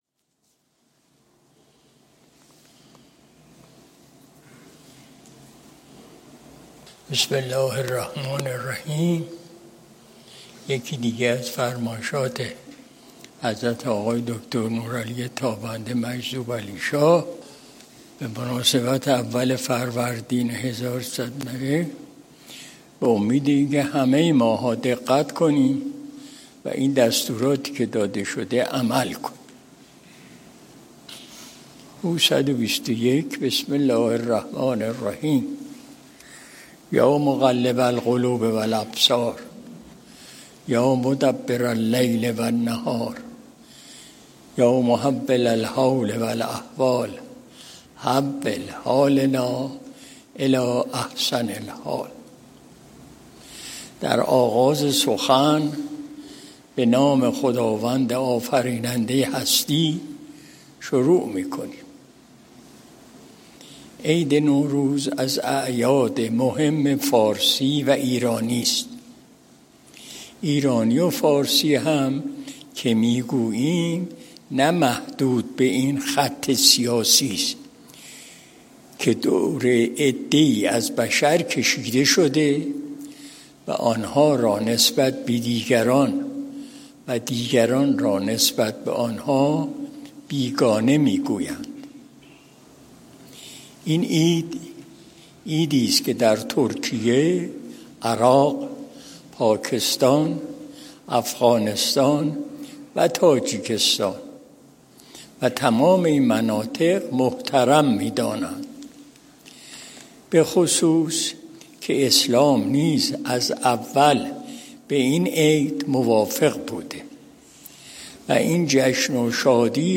مجلس شب دوشنبه ۲ بهمن ماه ۱۴۰۱ شمسی